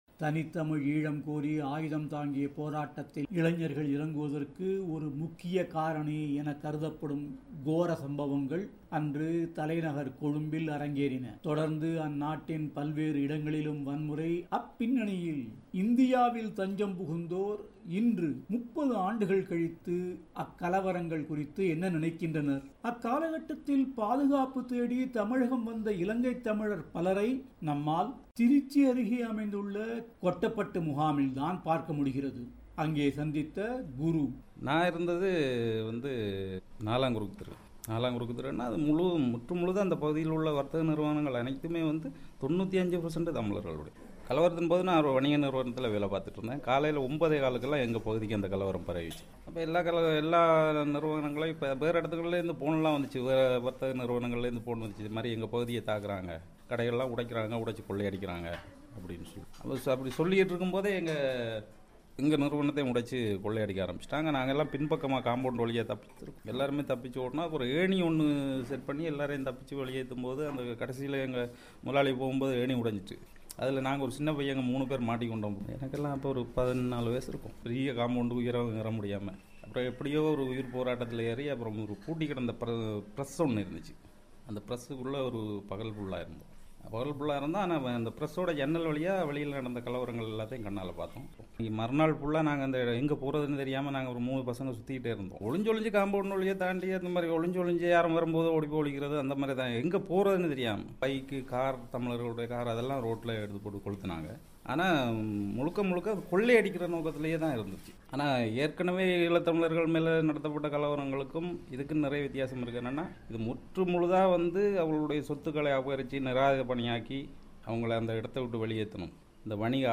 இலங்கையில் 'கறுப்பு ஜூலை' என்று பாதிக்கப்பட்ட மக்களால் கூறப்படும் அந்த வன்செயல்கள், அவைகளின் தாக்கம், விடுதலைப் புலிகளுடனான போர் முடிந்த நிலையில்,அடுத்து என்ன என்று பல விஷயங்கள் குறித்து இப்போது தமிழகத்தில் தங்கியுள்ள சிலர் பிபிசி தமிழோசையிடம் பகிர்ந்து கொண்ட தகவல்களை உள்ளடக்கிய சிறப்பு பெட்டகத்தை இங்கே கேட்கலாம்.